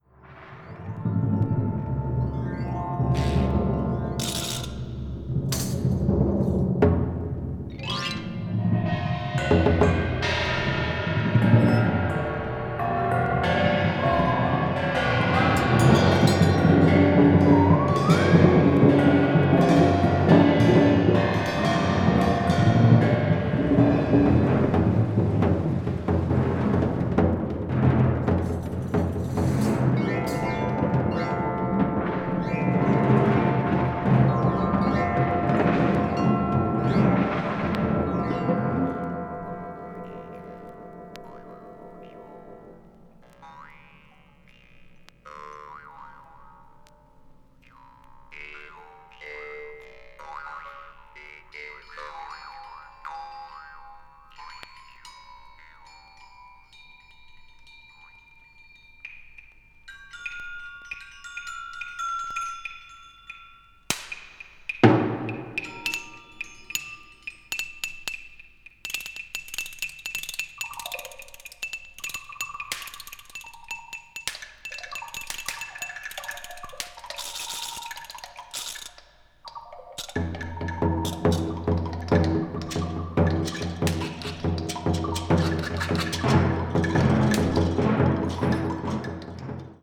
5人のブルガリア人ミュージシャンからなるグループ